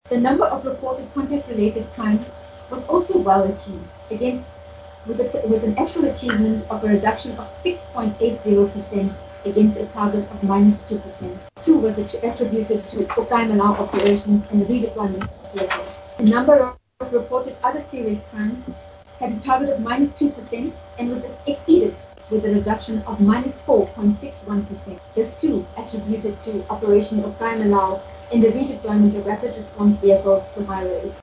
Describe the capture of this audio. Crime-stats-on-briefing-cut.mp3